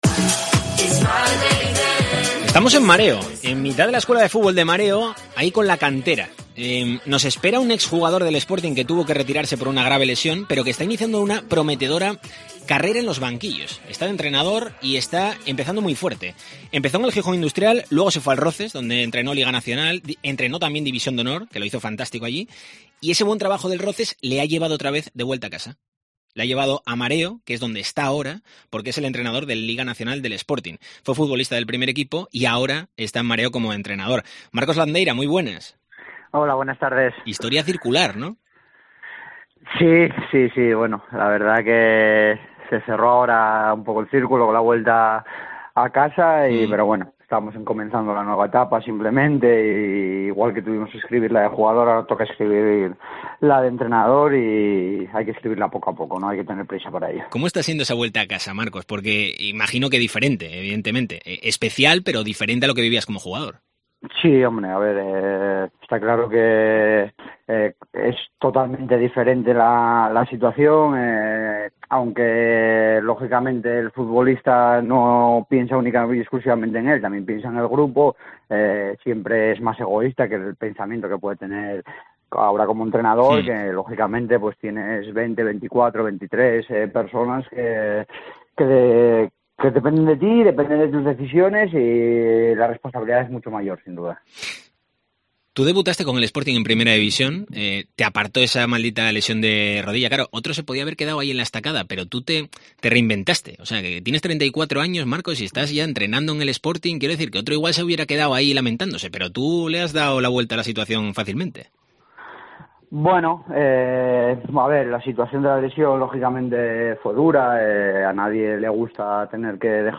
AUDIO: Entrevista con el ex jugador del Sporting, ahora entrenador del Liga Nacional